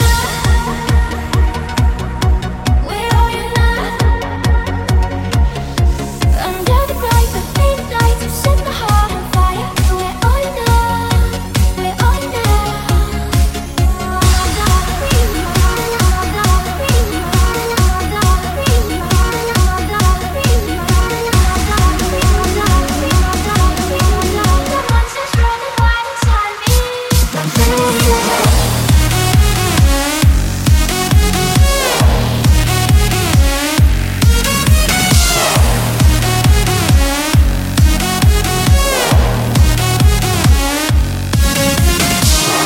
Genere: club, edm, successi, remix